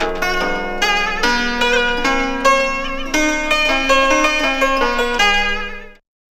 CHINAZITH3-R.wav